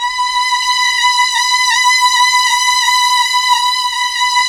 Index of /90_sSampleCDs/Roland LCDP09 Keys of the 60s and 70s 1/KEY_Chamberlin/STR_Chambrln Str